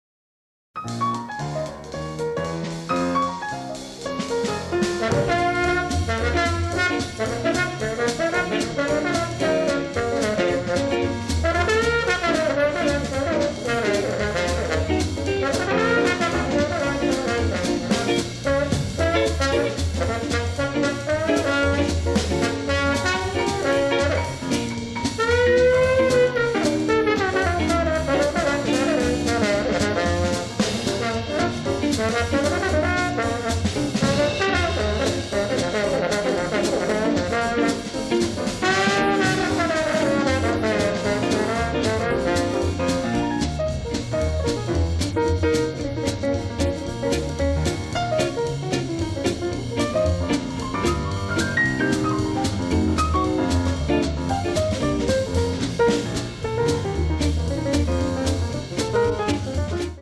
diegetic jazz and 1950s-styled dance tunes